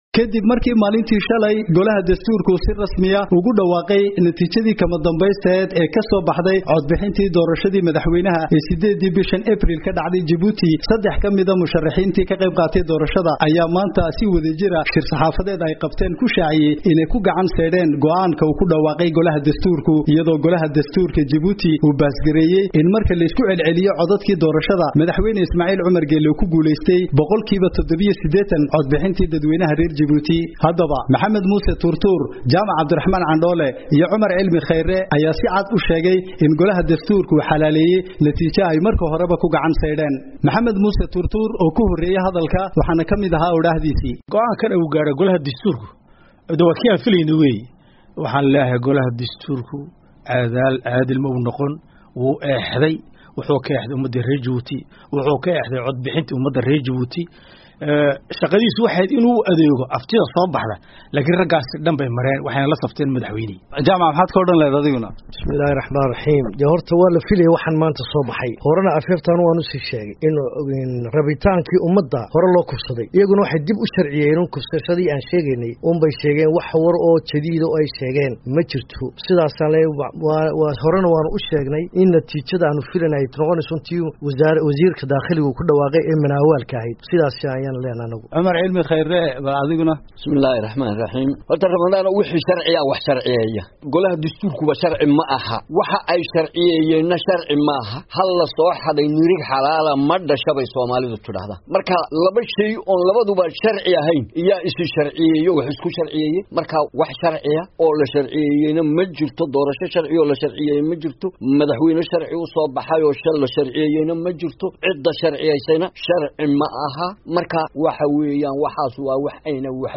Warbixin: Doorashada Jabuuti